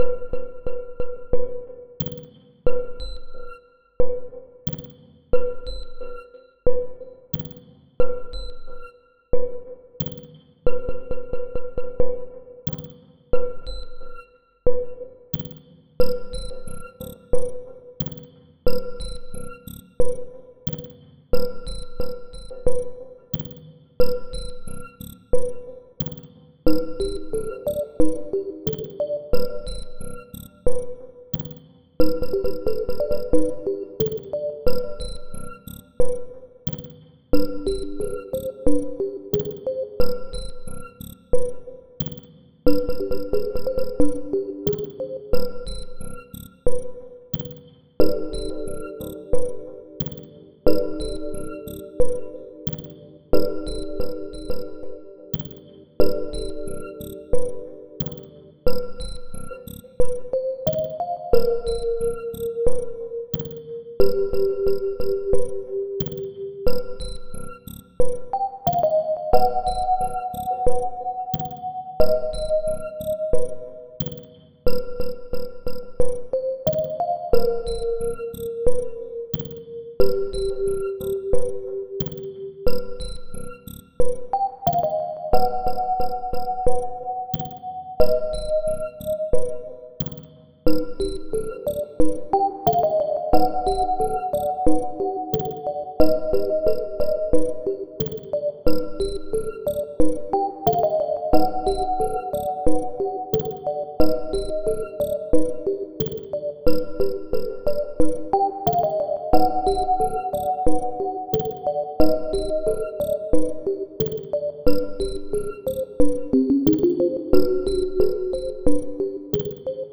Pieza de electrónica IDM-Ambient
Música electrónica
ambiente
melodía
sintetizador